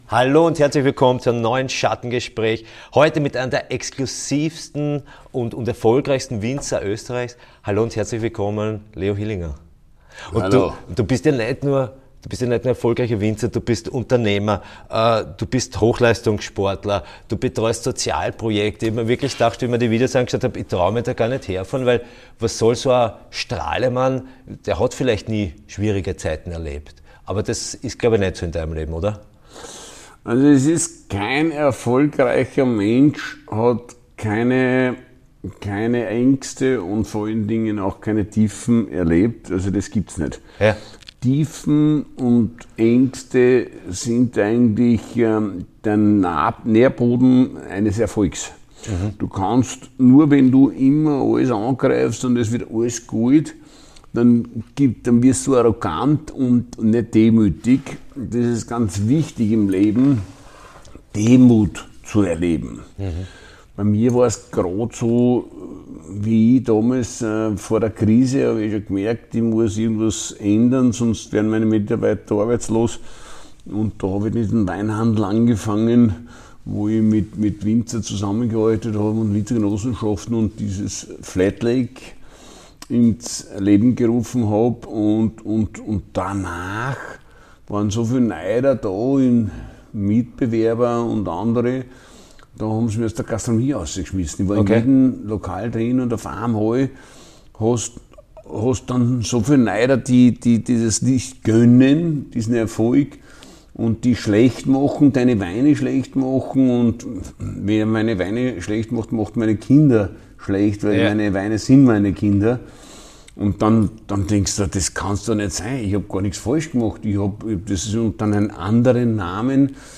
Ihm hat es gefallen und am Sonntag kannst du das Interview hören.